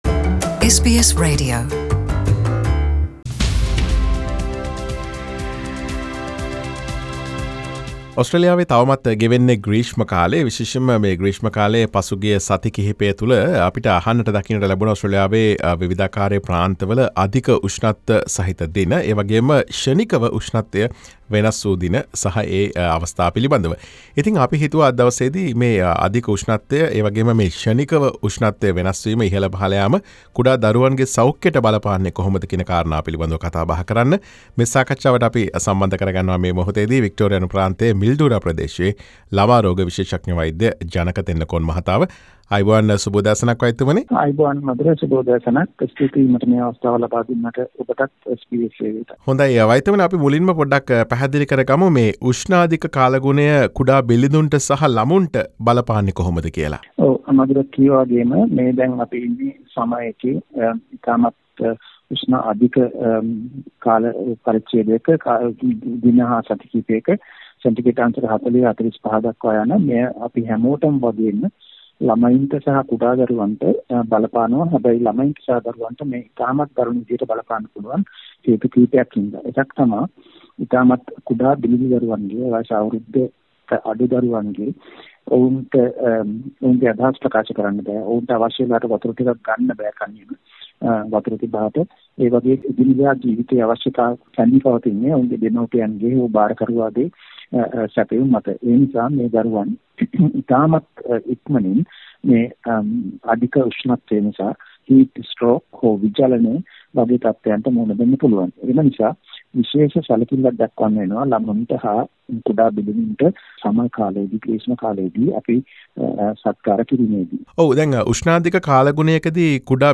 SBS සිංහල සිදු කළ සාකච්ඡාව.